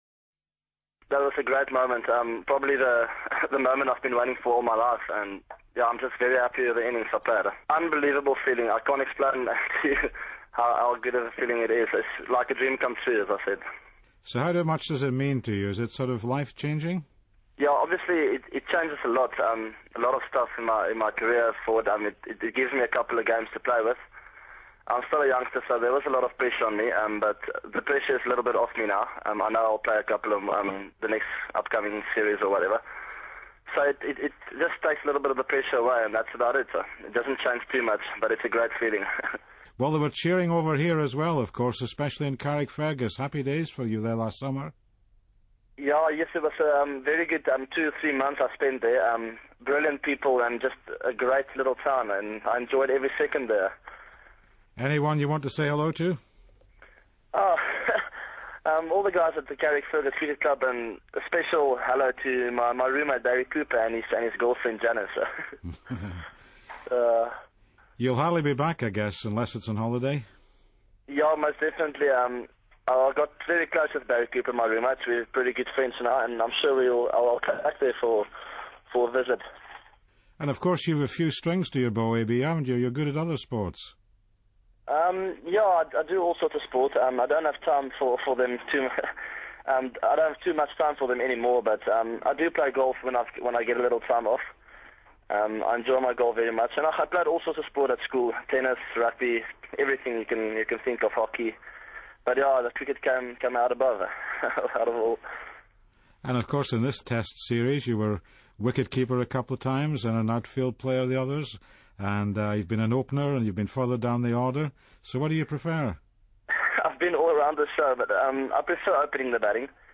AB_Interview.mp3